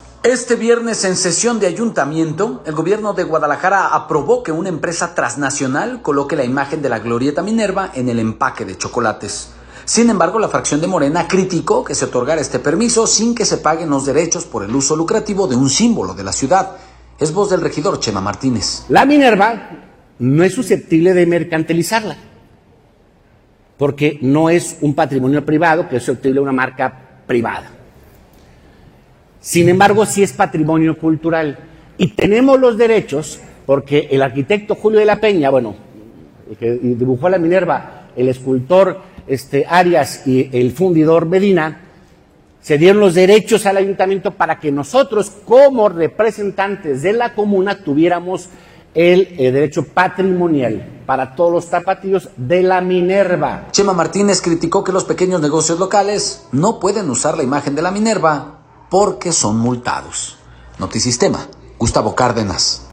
audio Este viernes, en sesión de Ayuntamiento, el Gobierno de Guadalajara aprobó que una empresa trasnacional coloque la imagen de la glorieta Minerva en el empaque de chocolates. Sin embargo la fracción de morena criticó que se otorgara el permiso sin que se paguen derechos por el uso lucrativo de un símbolo de la ciudad. Es voz del regidor “Chema” Martínez.